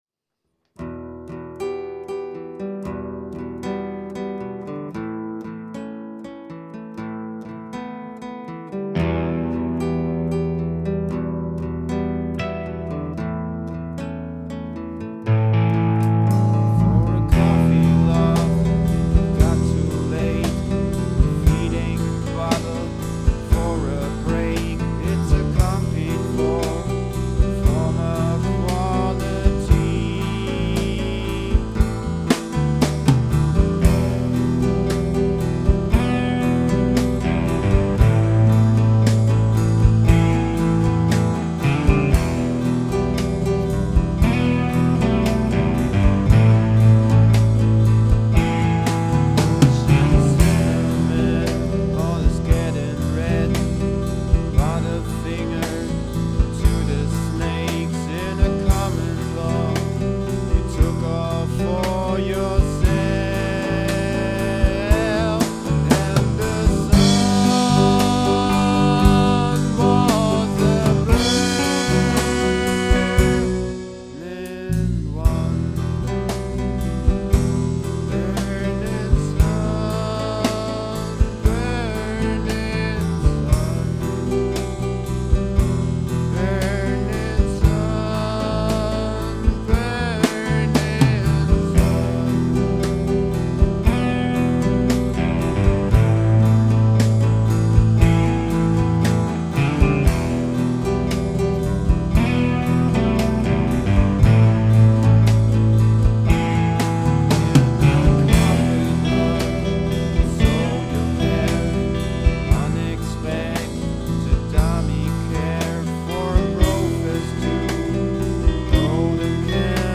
singing and guitar
drums
bass guitar and recording